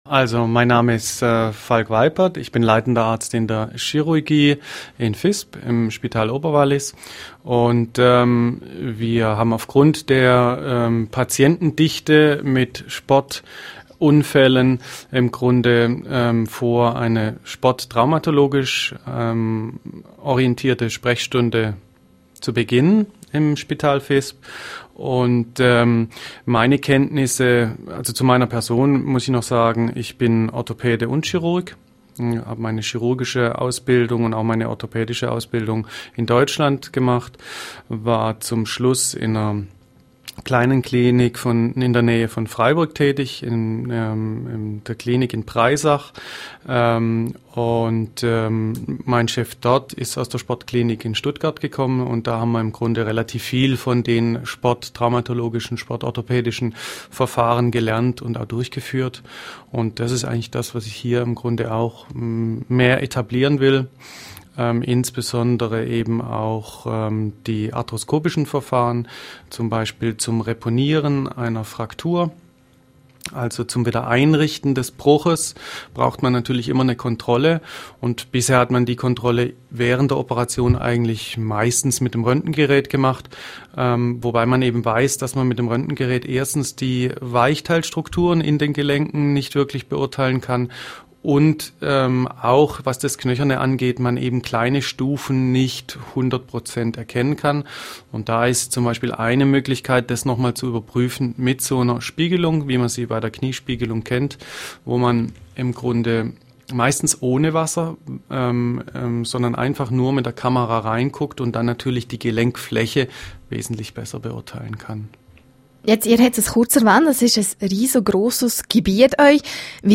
Sportmedizin: Interview